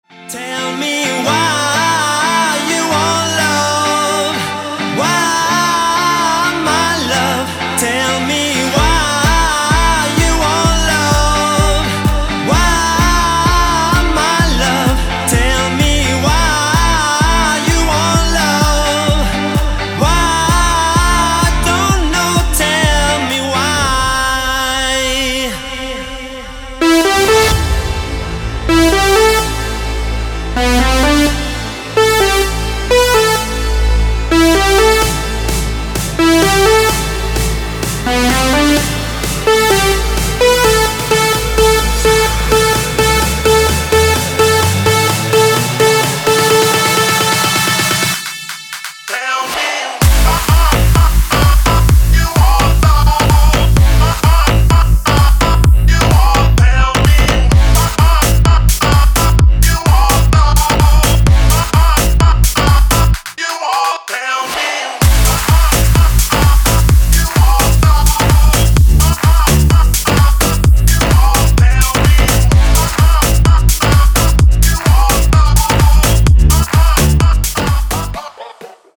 • Качество: 320, Stereo
мужской вокал
громкие
электронная музыка
нарастающие
house
electro house